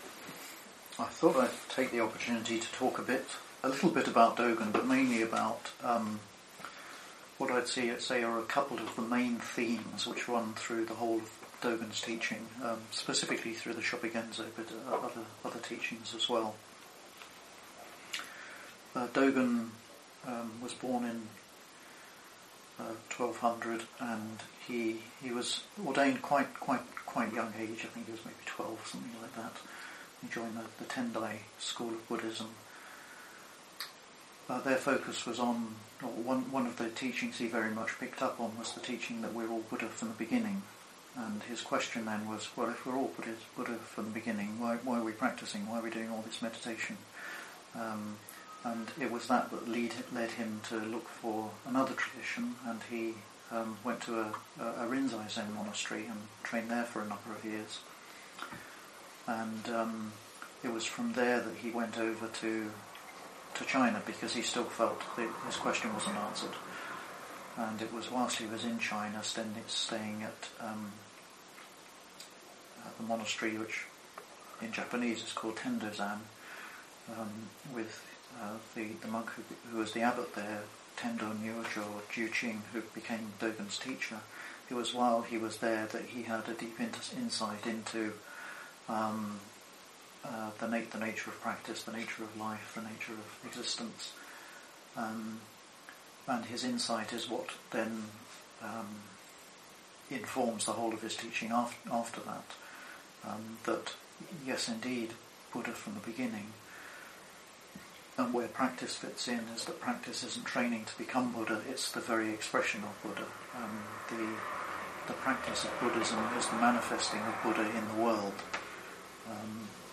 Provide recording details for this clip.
This talk was given at the Festival of Great Master Dōgen at Turning Wheel Buddhist Temple in August 2015.